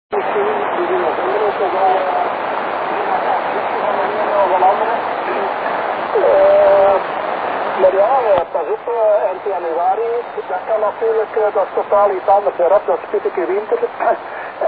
noise_ssb.mp3